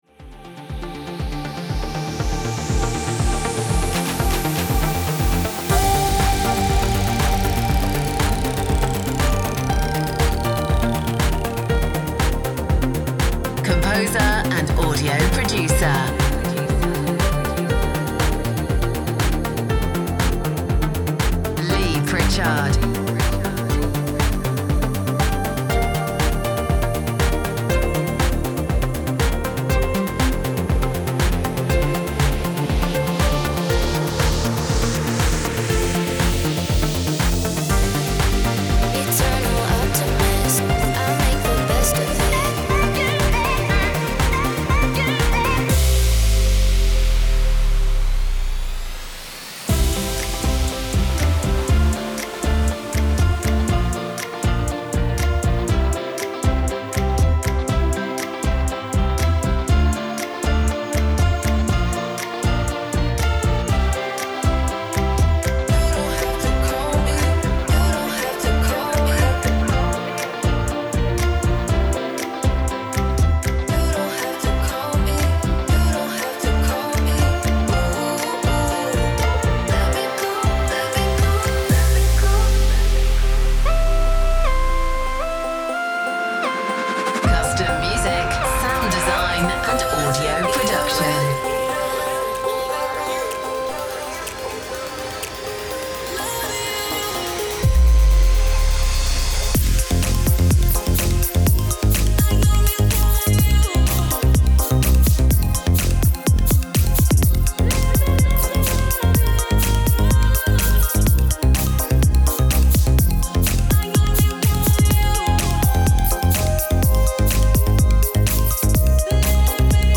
Dance Music
these tracks include club beats, trance, and EDM.